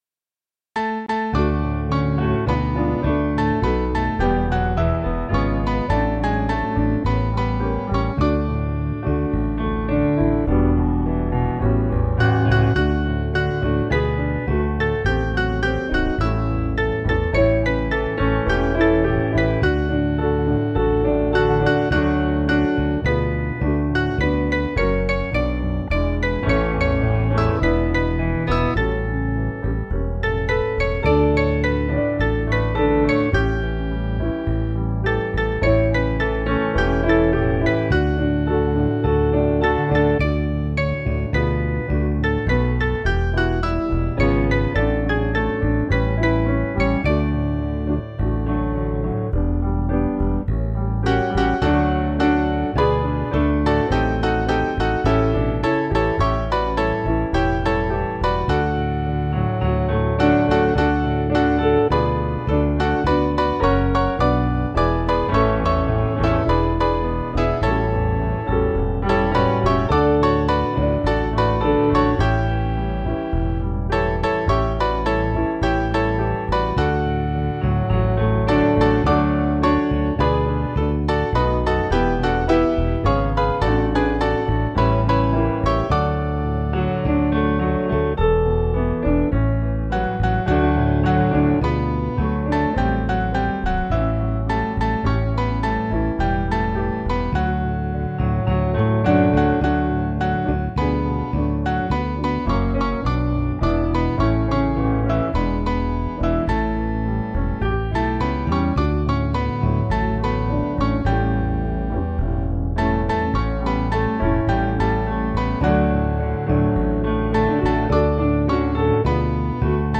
Mainly Piano